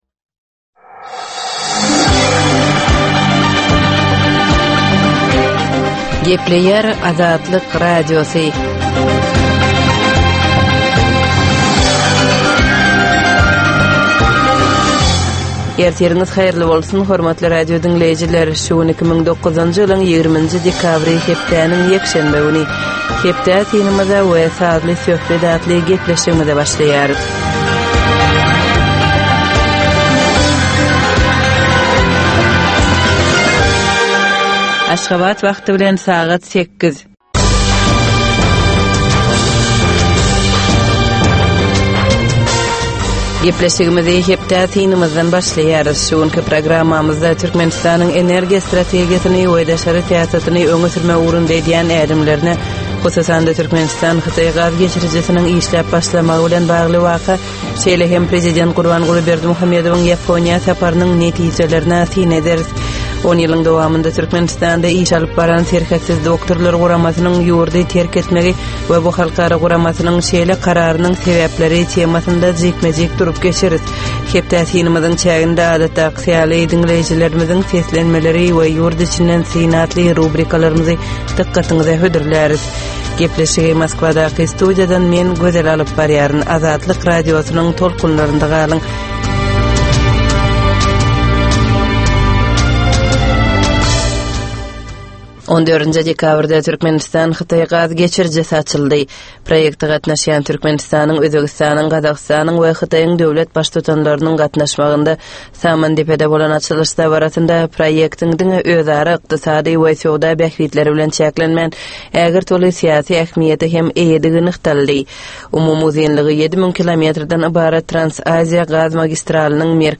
Tutuş geçen bir hepdäniň dowamynda Türkmenistanda we halkara arenasynda bolup geçen möhüm wakalara syn. 30 minutlyk bu ýörite programmanyň dowamynda hepdäniň möhüm wakalary barada gysga synlar, analizler, makalalar, reportažlar, söhbetdeşlikler we kommentariýalar berilýär.